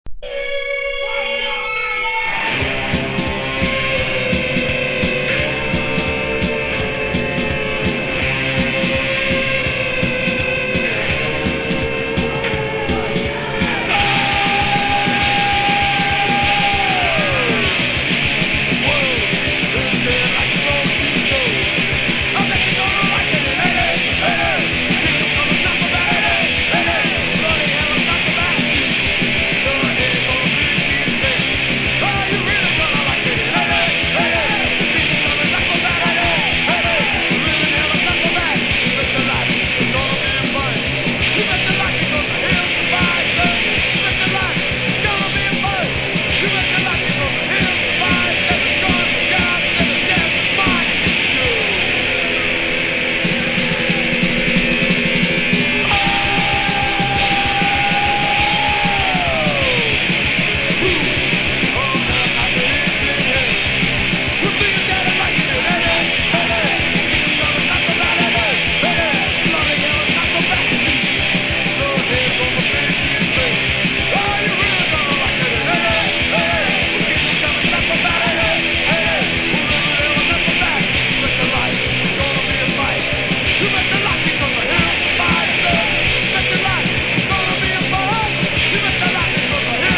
メロディのセンスと、凶悪なディストーション・ギターが最強!!